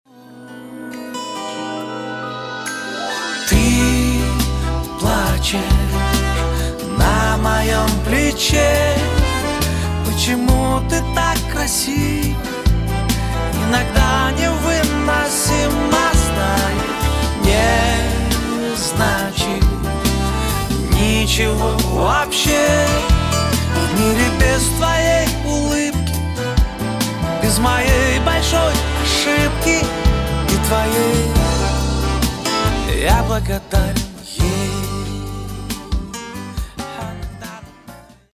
А мне чёт дудок а-ля "Kool & the Gang" не хватило.